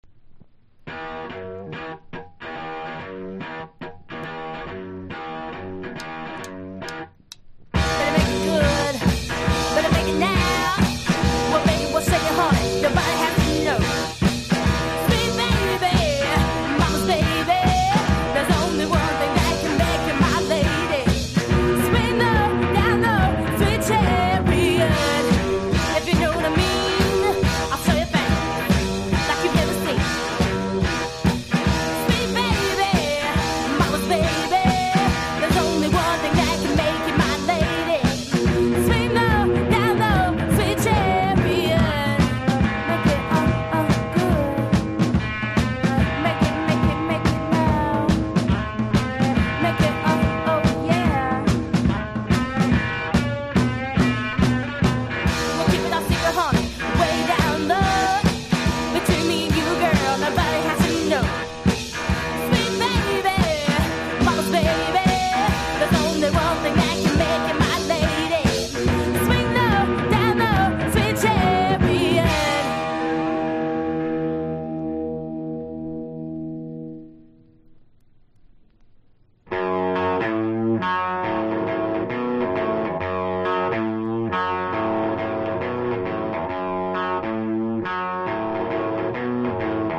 # GARAGE ROCK (90-20’s)